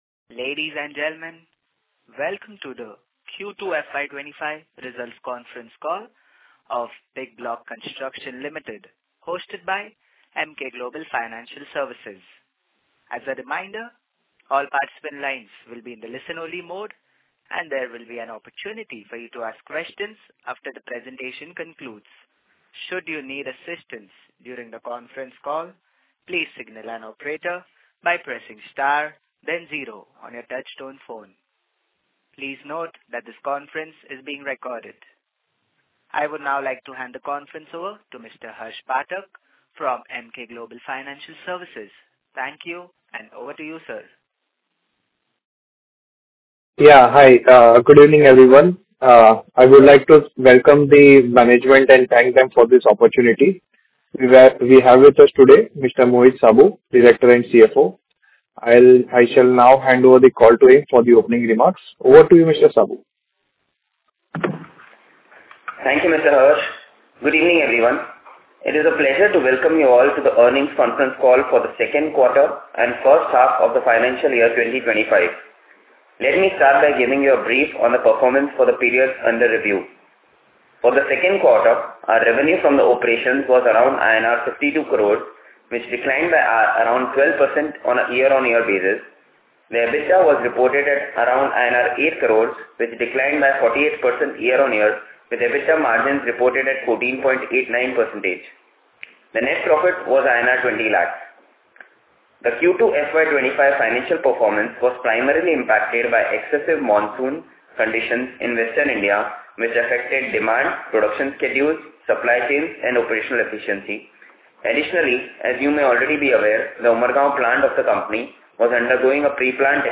Bigbloc-Construction_Q2FY25-Earnings-Concall-Recording.mp3